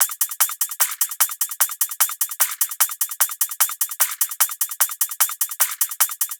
VR_top_loop_hectic2_150.wav